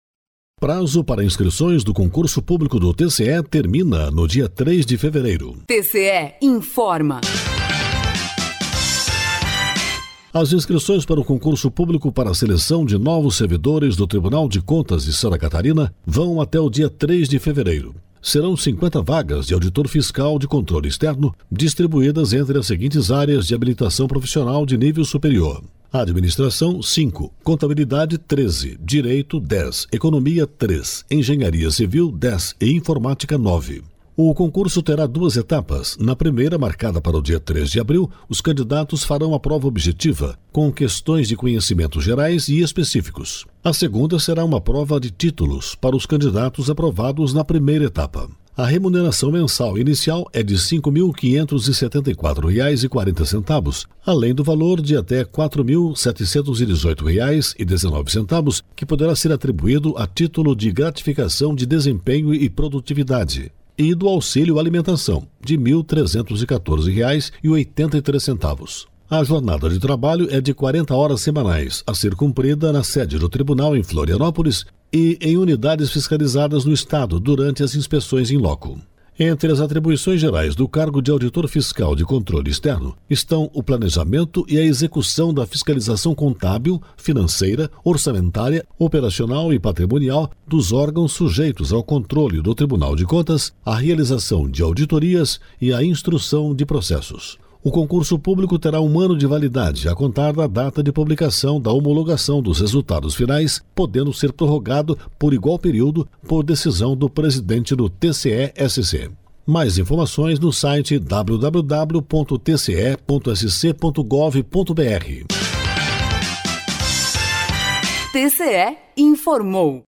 (apresentador)